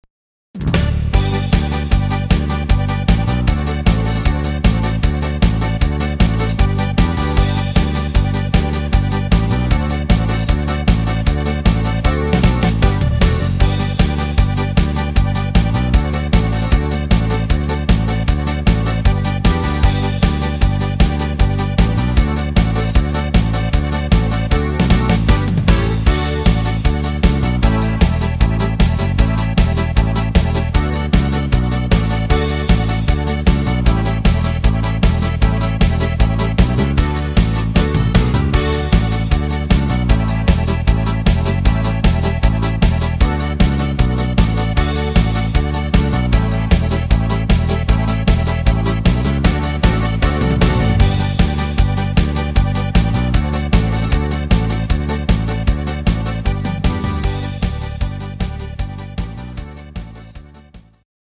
Akkordeon